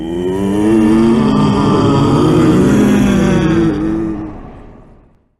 Bloodborne Message Appear Sound Effect Free Download